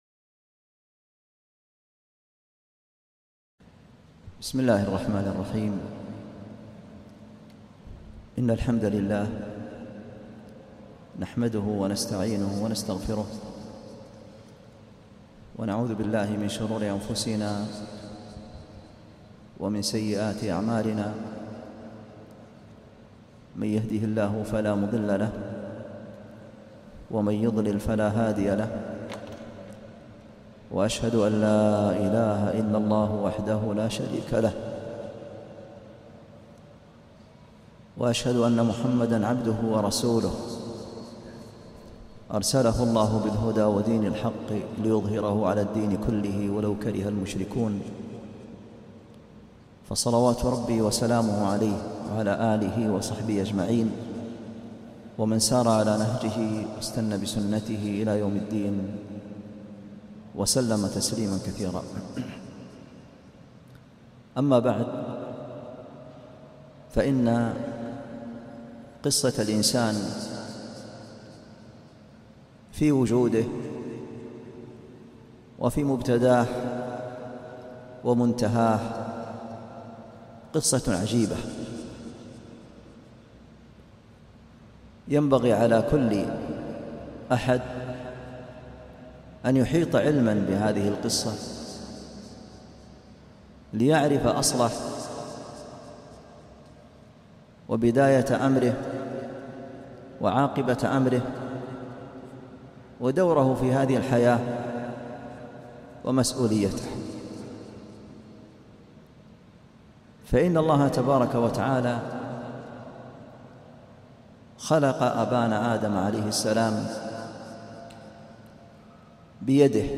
محاضرة -( ُثمَّ جَعَلناكَ عَلى شَريعَةٍ مِنَ الأَمرِ﴾